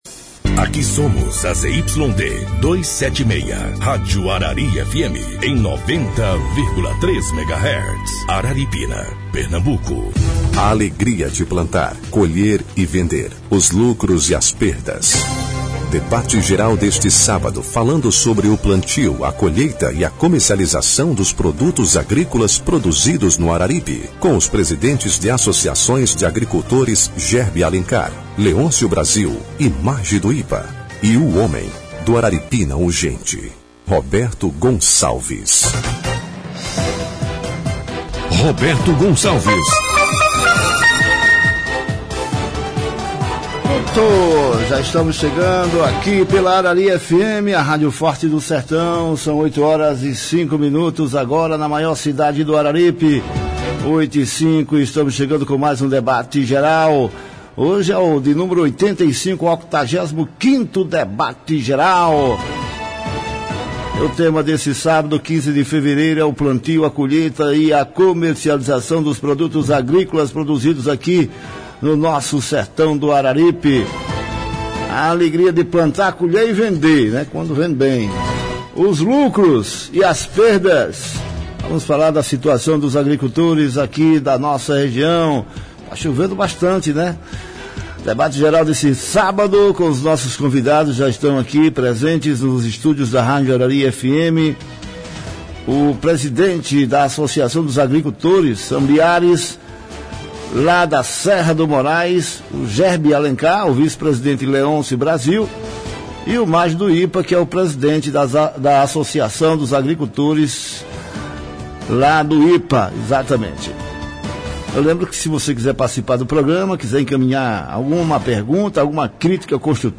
Participaram do programa na Arari FM 90,3, os presidentes de associações de agricultores familiares